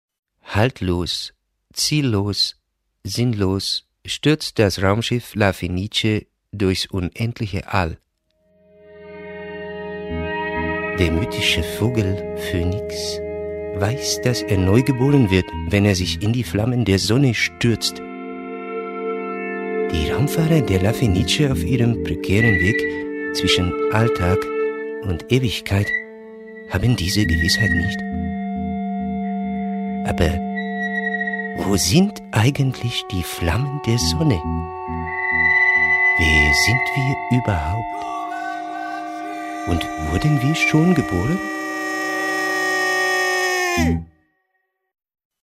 Bayerische Heimatkultur oder bundespolitische Positionierung – darüber , wie das politische Grundsatzprogramm einzuschätzen ist, wollen wir nun mit dem Parteienforscher